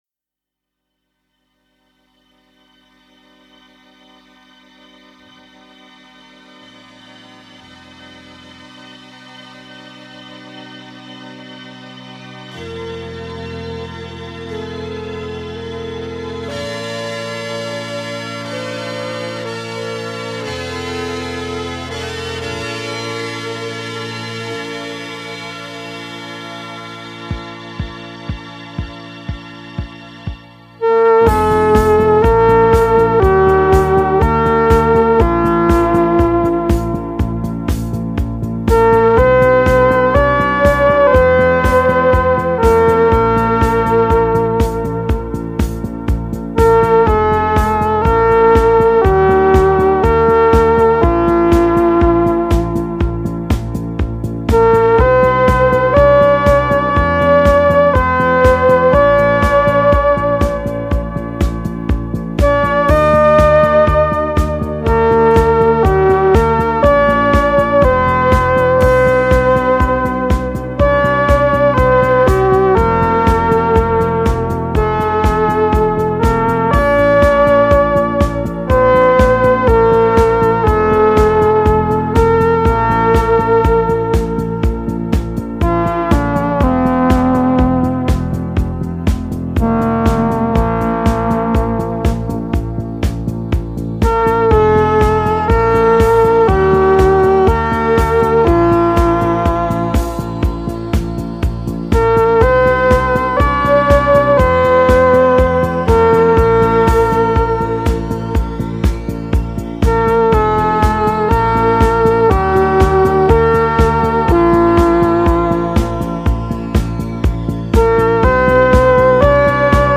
Petite crotte rock douce